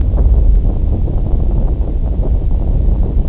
1 channel
RUMBLE1.mp3